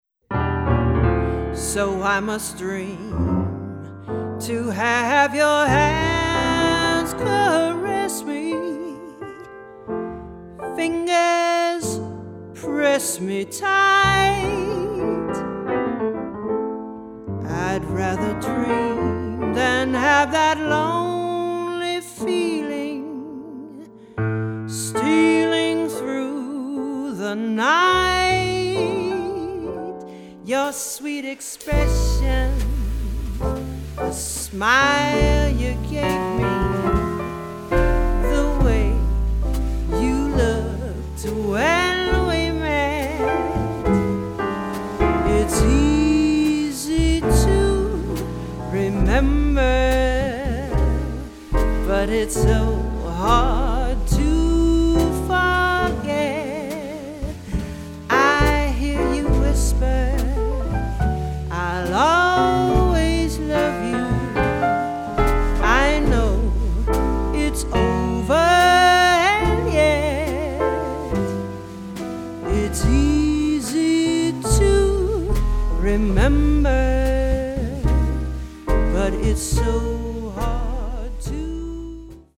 tenor sax and vocals
piano
bass
-drums